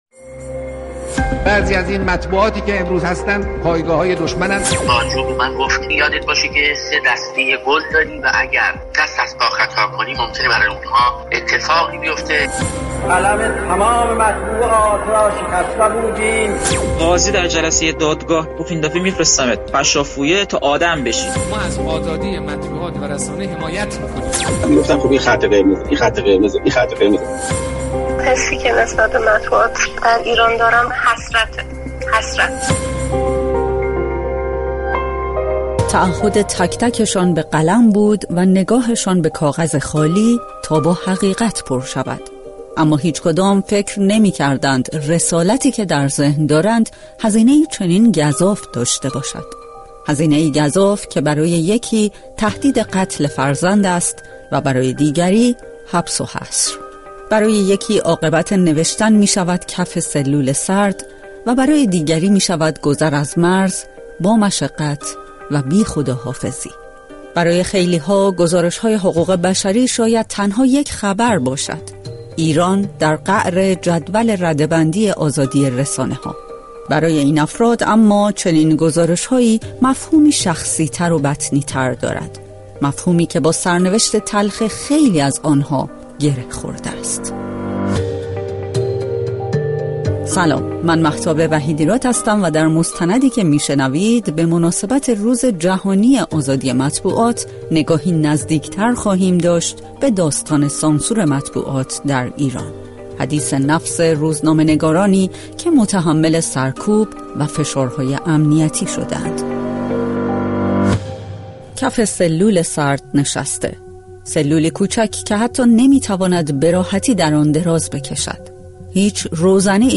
مستند رادیویی؛ هیس! این قلم شکستنی‌ست